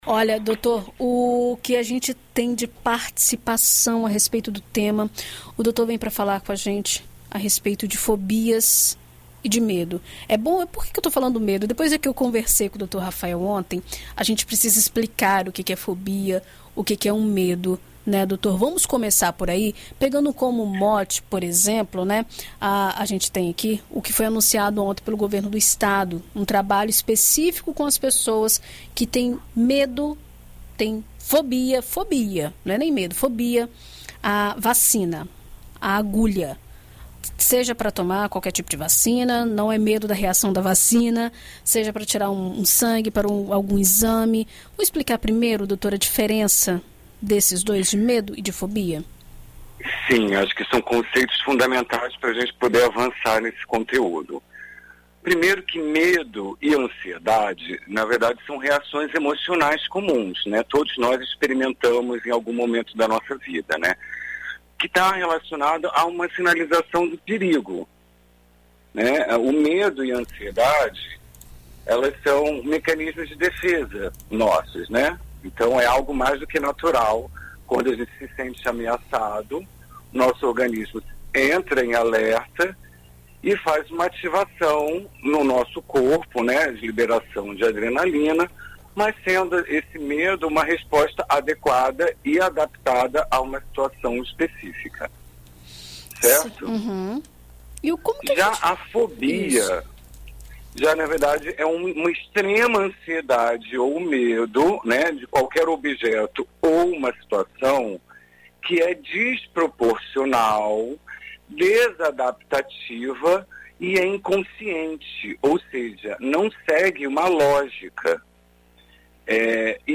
Em entrevista à BandNews FM Espírito Santo